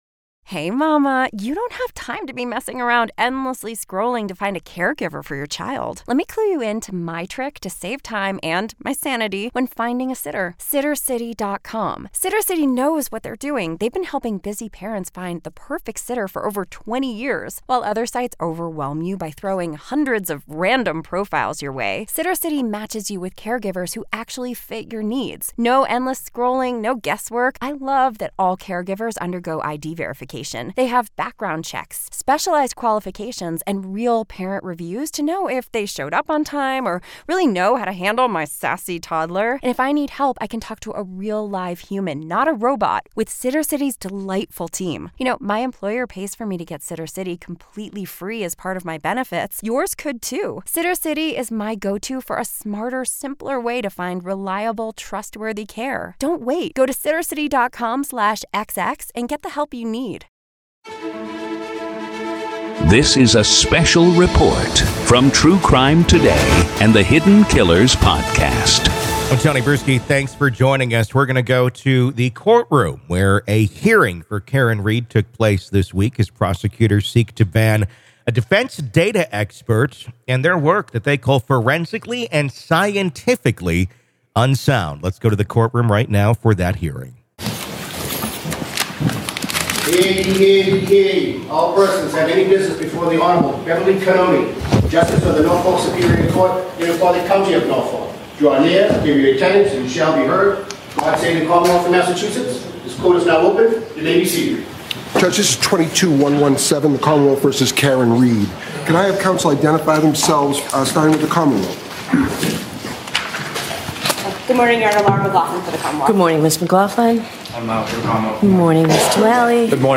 RAW COURT AUDIO: PART 1-Karen Read Hearing Begins With Heated Legal Battle Over Expert Testimony